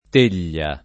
t%l’l’a] s. f. — ant. tegghia [t%ggLa]: Com’a scaldar si poggia tegghia a tegghia [k1m a Skald#r Si p0JJa t%ggLa a tt%ggLa] (Dante) — sim. il pers. m. stor.